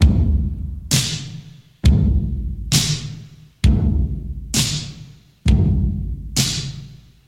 • 132 Bpm Old School Drum Loop Sample C Key.wav
Free breakbeat sample - kick tuned to the C note. Loudest frequency: 1596Hz
132-bpm-old-school-drum-loop-sample-c-key-bmK.wav